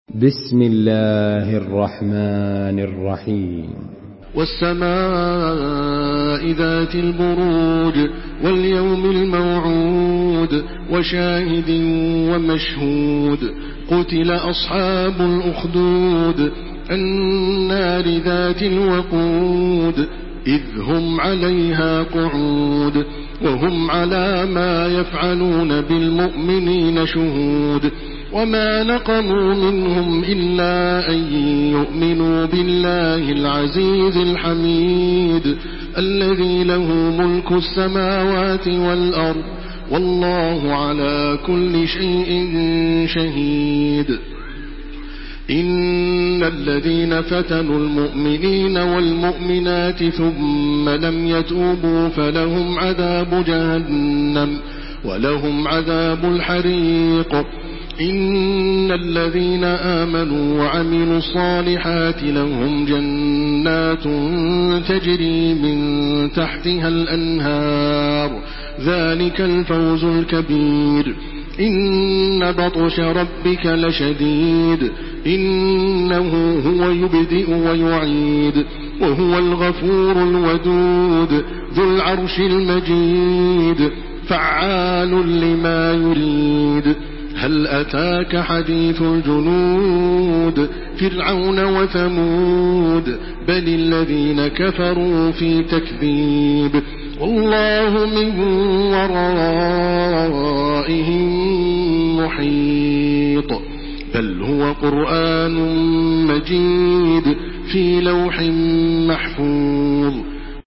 Surah البروج MP3 by تراويح الحرم المكي 1429 in حفص عن عاصم narration.